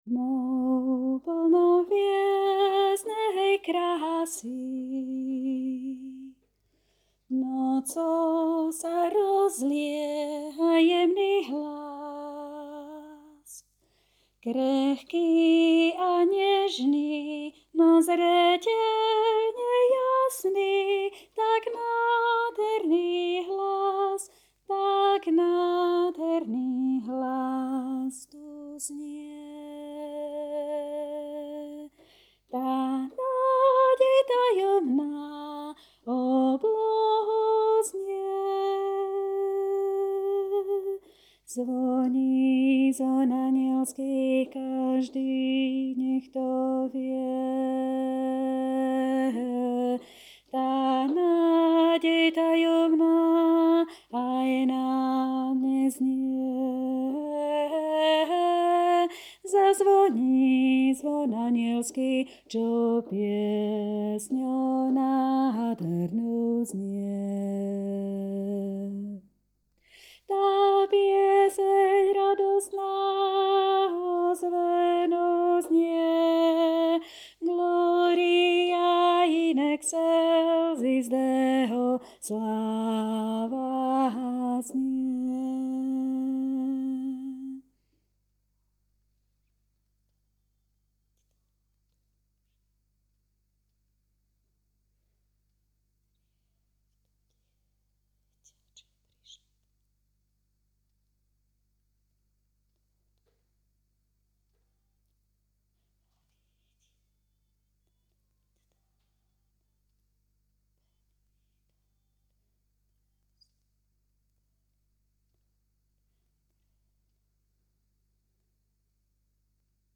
00:00 00:00 MP3 na stiahnutie Všetky zvončeky v raji (Soprán) Všetky zvončeky v raji (Alt) Všetky zvončeky v raji (Tenor) Všetky zvončeky v raji (Bass)